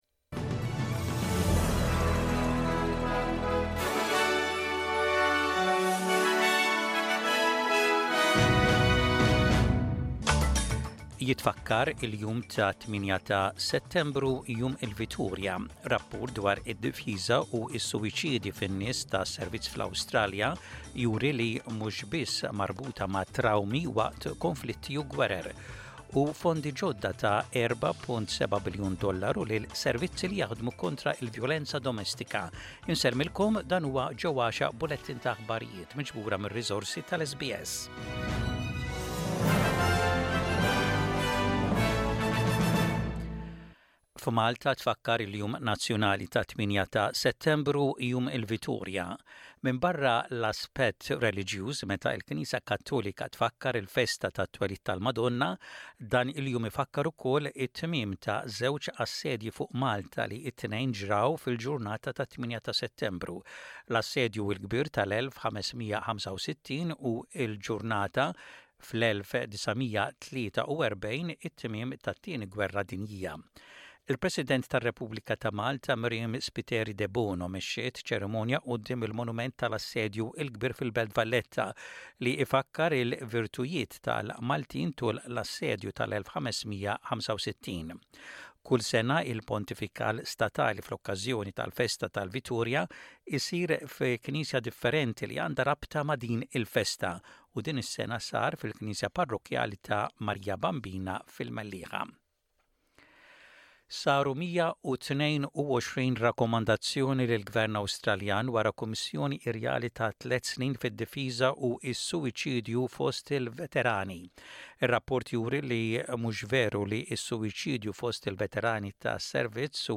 SBS Radio | Aħbarijiet bil-Malti: 10.09.24